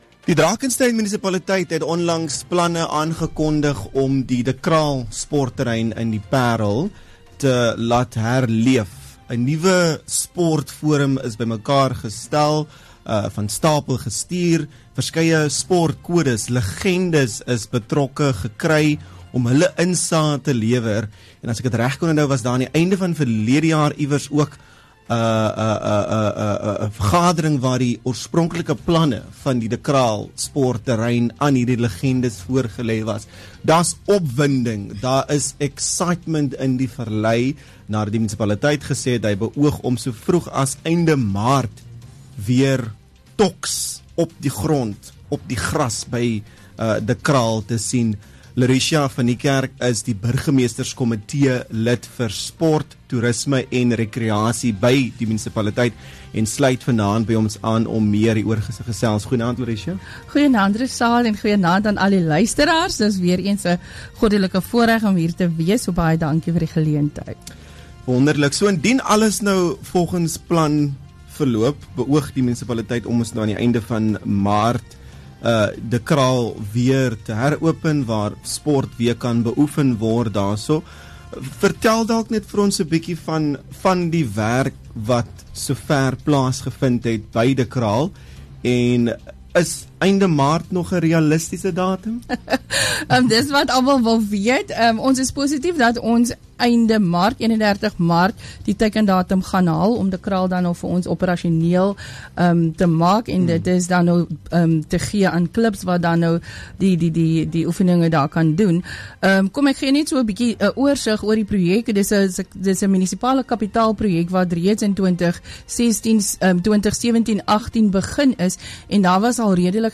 We speak to MMC for Tourism, Sport and Recreation Laurichia van Niekerk.